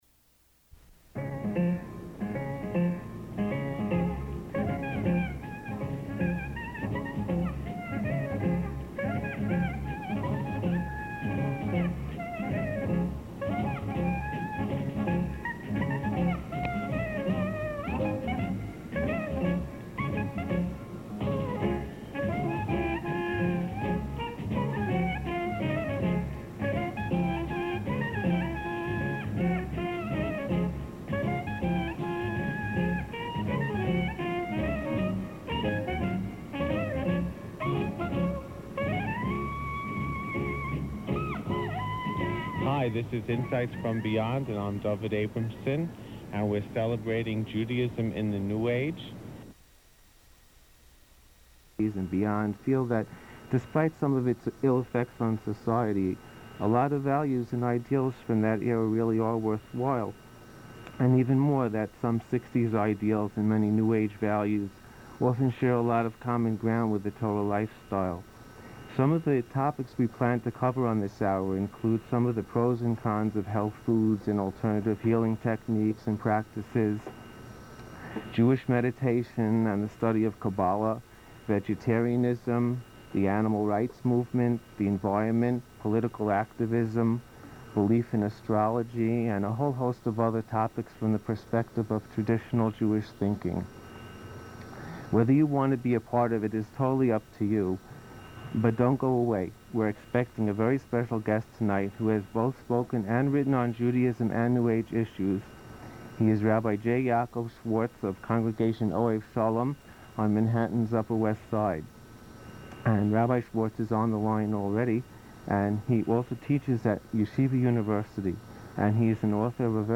..from the radio broadcast Insights from Beyond.